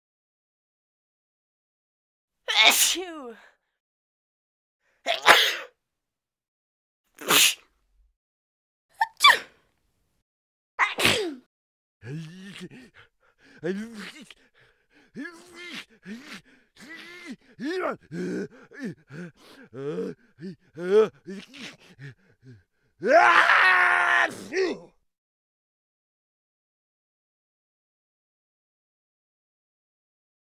دانلود آهنگ عطسه 1 از افکت صوتی انسان و موجودات زنده
دانلود صدای عطسه 1 از ساعد نیوز با لینک مستقیم و کیفیت بالا
جلوه های صوتی
برچسب: دانلود آهنگ های افکت صوتی انسان و موجودات زنده دانلود آلبوم انواع صدای عطسه – مرد و زن از افکت صوتی انسان و موجودات زنده